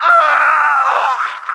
legodeath.ogg